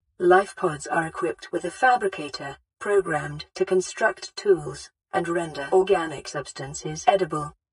UpdatedAISpeech-2.ogg